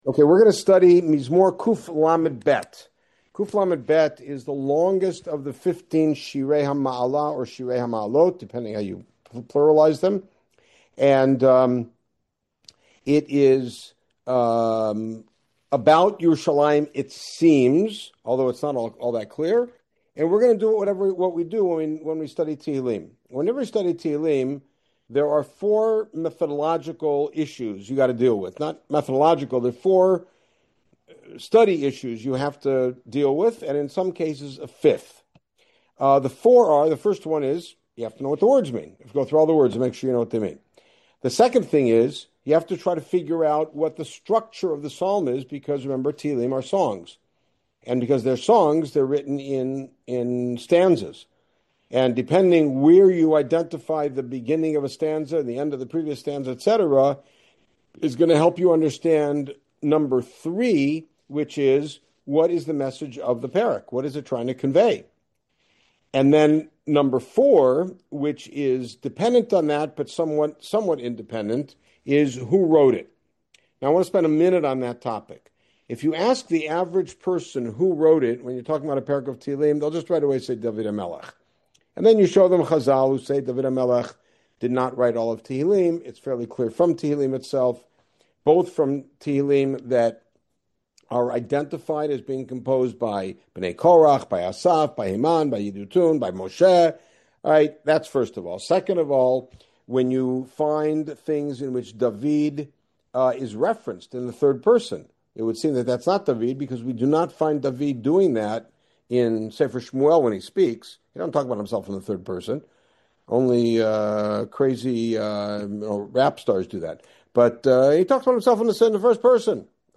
What was the occasion that motivated the composition of Psalm 132? A special shiur as we prepare for Yom Yerushalayim